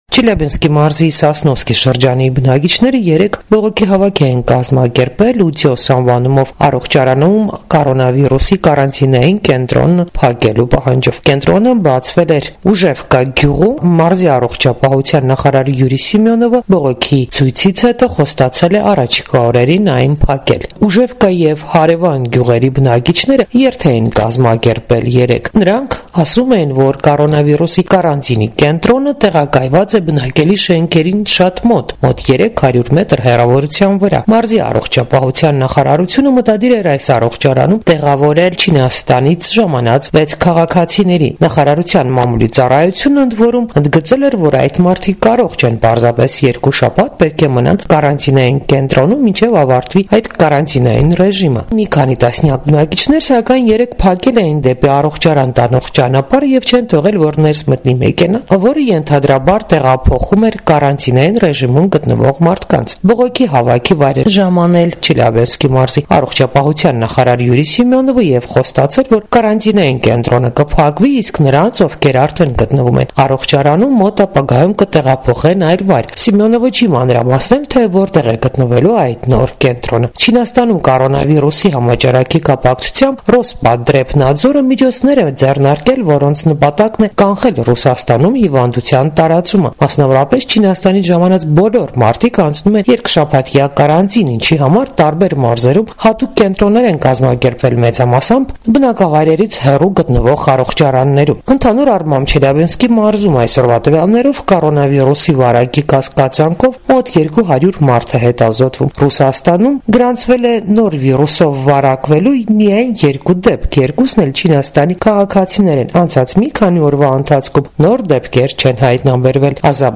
Ռեպորտաժներ
Կորոնավիրուսի կարանտինային կենտրոնը փակելու պահանջով բողոքի ակցիա ՌԴ Չելյաբինսկի մարզում